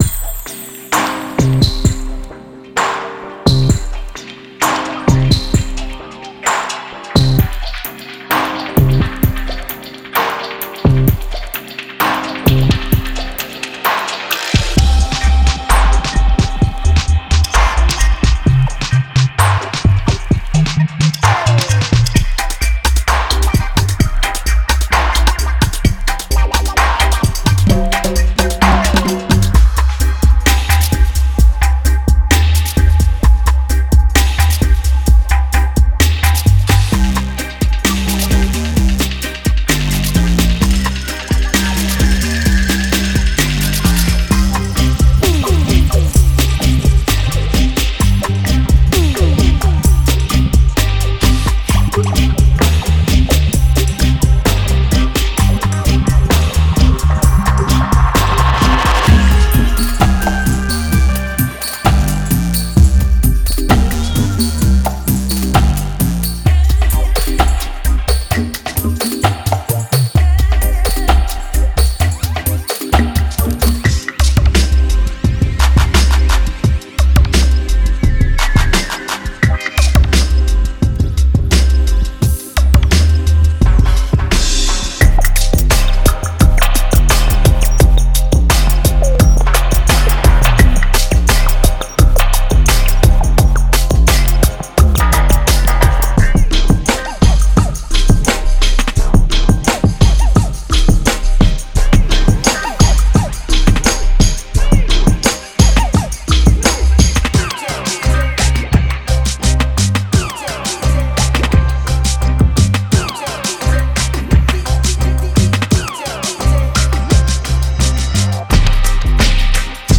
Bongos
Congas
デモサウンドはコチラ↓
Genre:Dub
Tempo Range: 72-175BPM
400+ Loops
400+ Oneshots